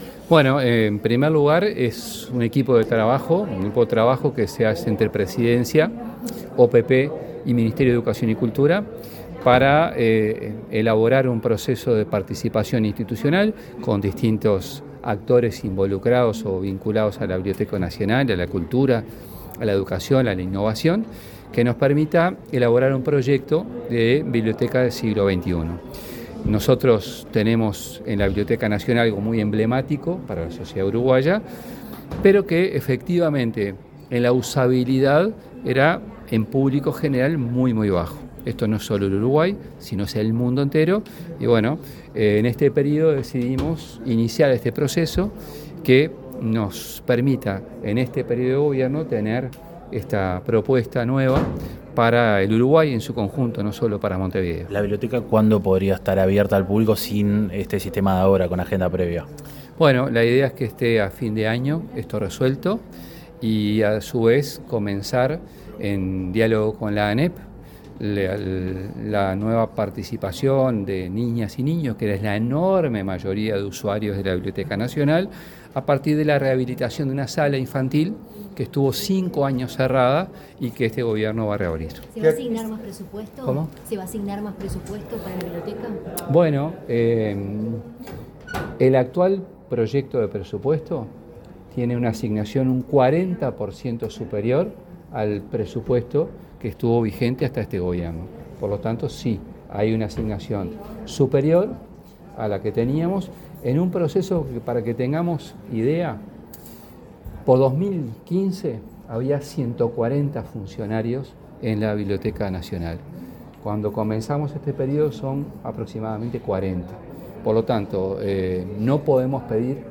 Declaraciones del ministro de Educación, José Carlos Mahía
Tras participar de la conferencia de prensa en la que se presentó el plan de trabajo para la Biblioteca Nacional del Futuro, el ministro de Educación y Cultura, José Carlos Mahía, dialogó con la prensa